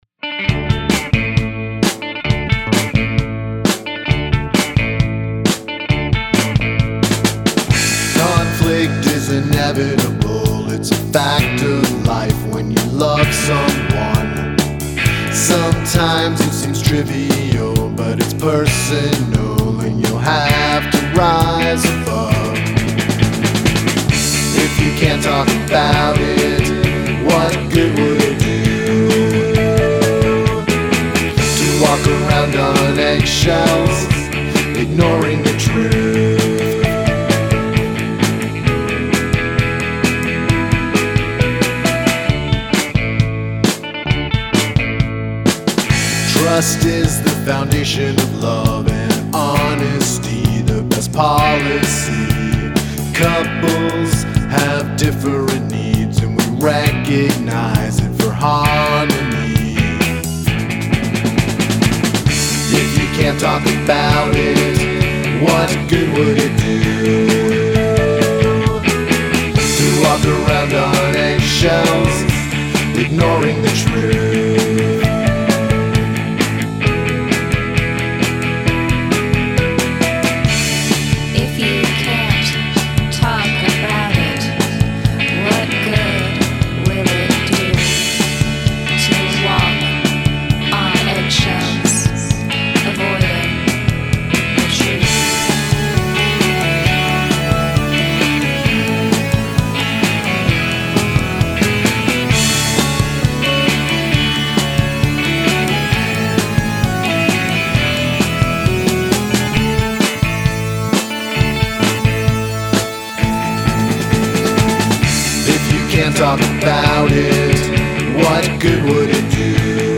Guest spoken word